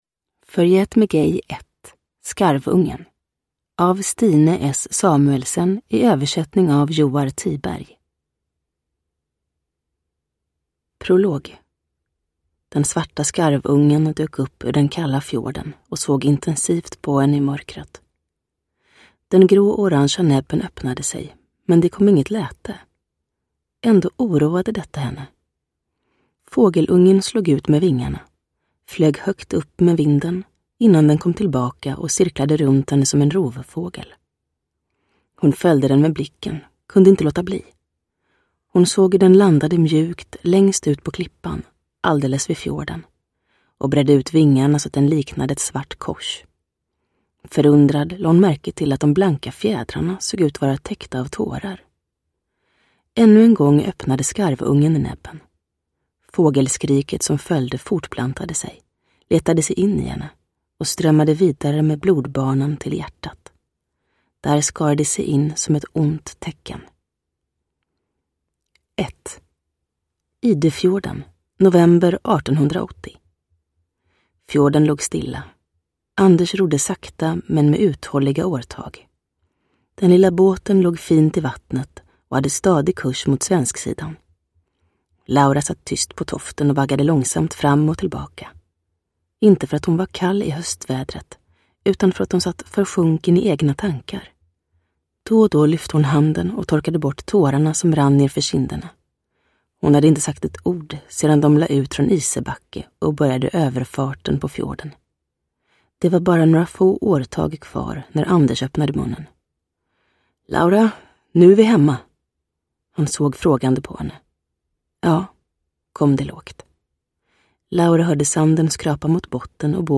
Uppläsare: Julia Dufvenius